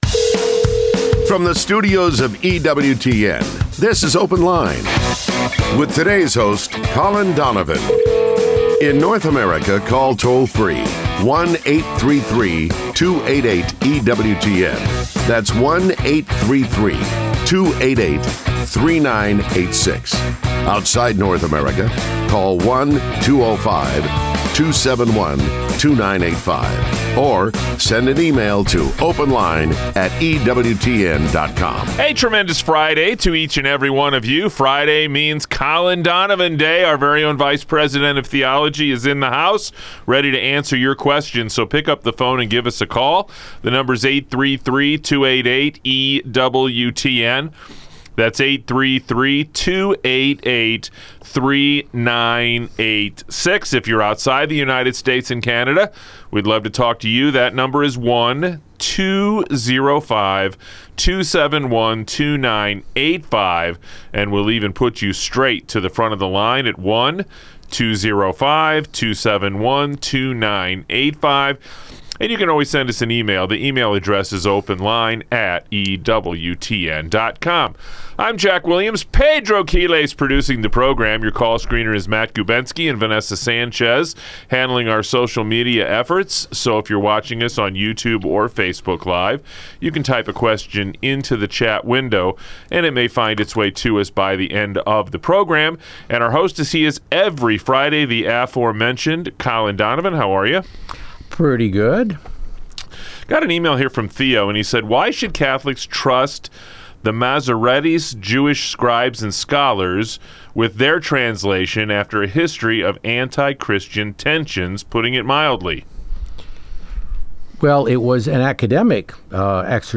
Open Line is a fast-paced call-in show, with a new host every day!